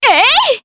Juno-Vox_Attack4_jp.wav